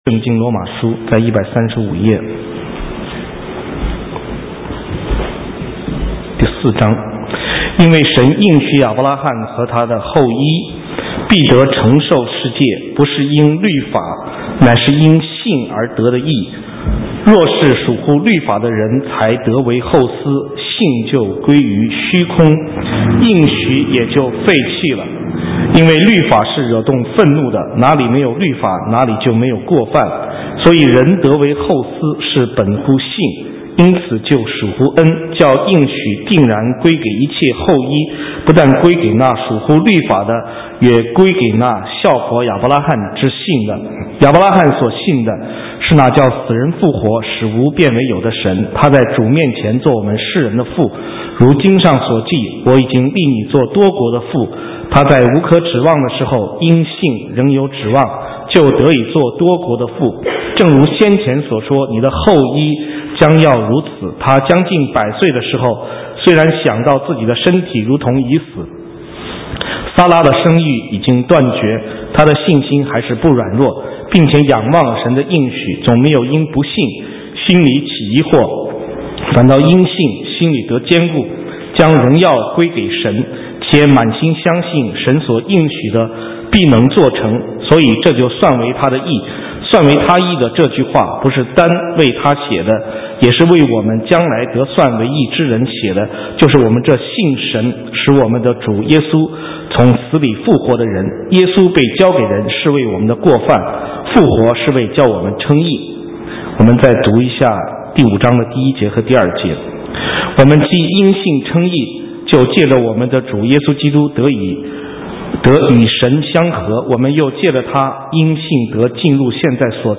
神州宣教--讲道录音 浏览：真信心的宝贵 (2012-03-11)